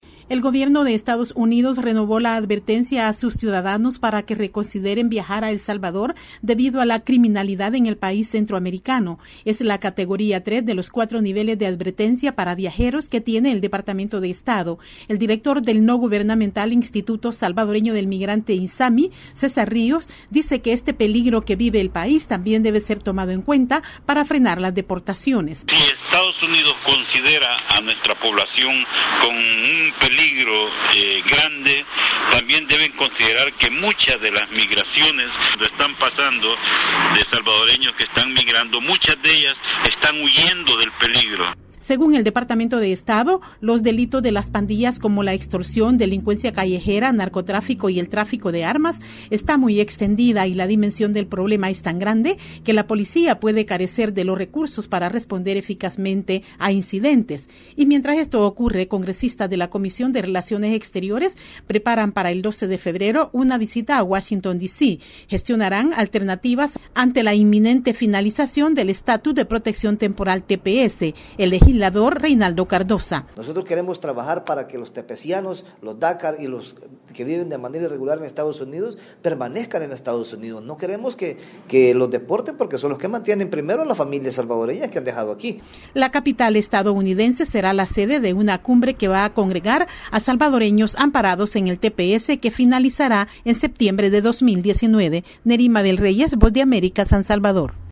VOA: Informe de El Salvador